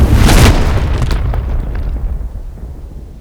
crash_rock-cinematic.wav